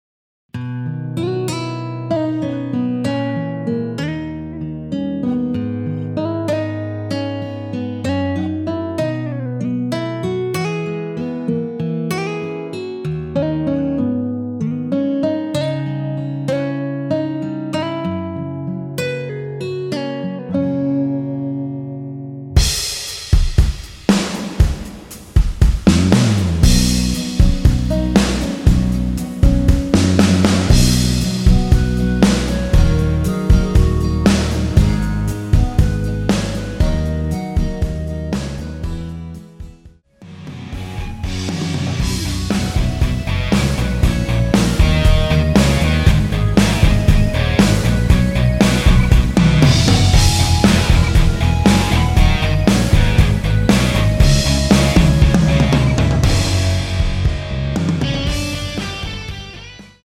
원키에서(-1)내린 멜로디 포함된 MR입니다.
◈ 곡명 옆 (-1)은 반음 내림, (+1)은 반음 올림 입니다.
앞부분30초, 뒷부분30초씩 편집해서 올려 드리고 있습니다.
중간에 음이 끈어지고 다시 나오는 이유는